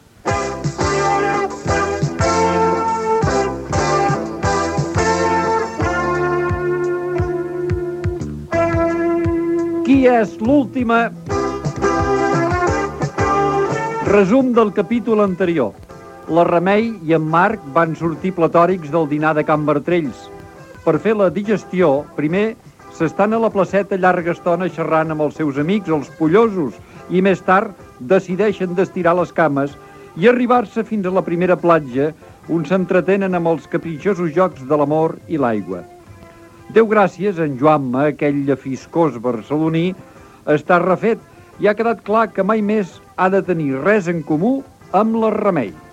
913a027271d1193ae8538fd055fa24b1792ce974.mp3 Títol Ràdio Arenys Emissora Ràdio Arenys Titularitat Pública municipal Nom programa Qui és l'última? Descripció Resum del capítol anterior. Gènere radiofònic Ficció